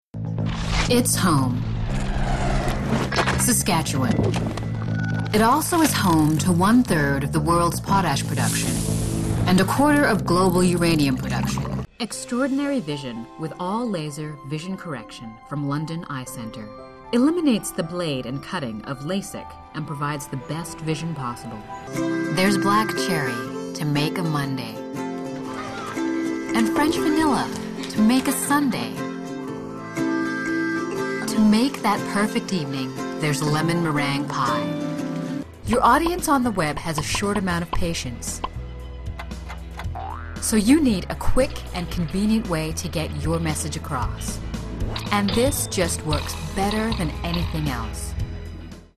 Commercial Demo
Middle Aged
My warm and confident delivery ensures your message resonates with your audience.